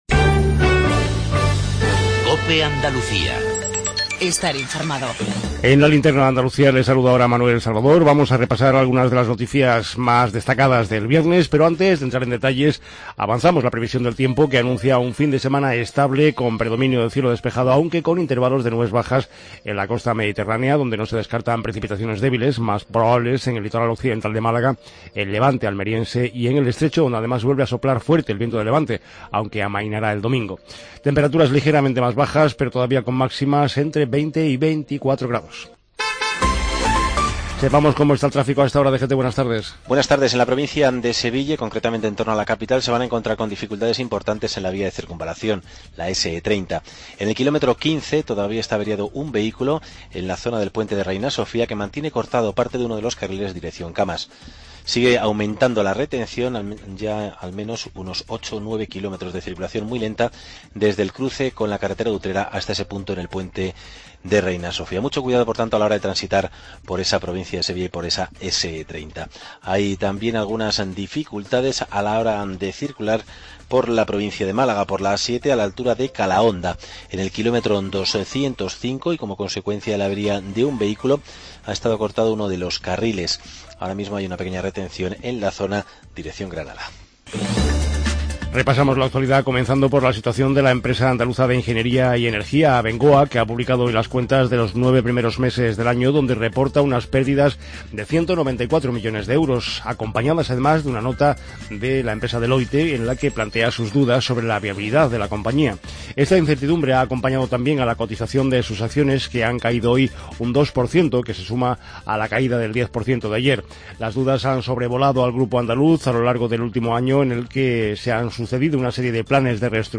INFORMATIVO REGIONAL TARDE COPE ANDALUCIA